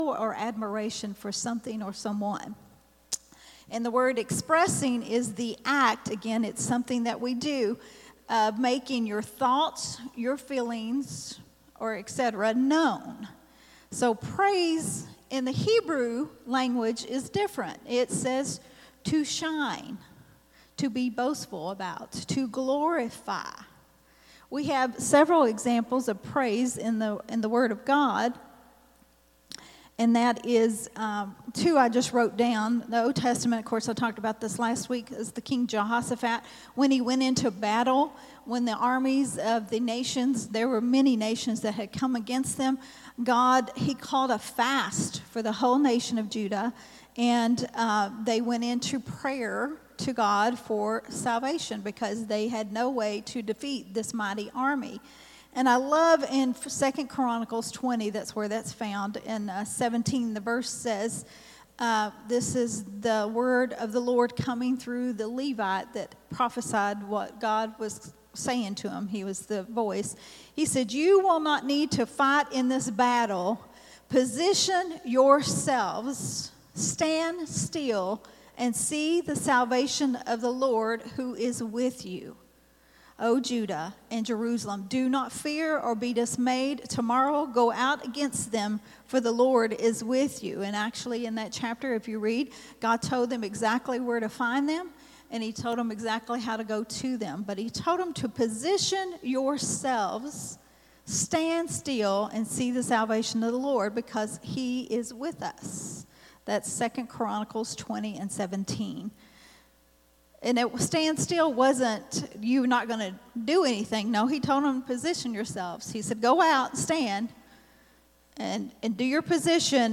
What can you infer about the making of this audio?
recorded at Growth Temple Ministries on Sunday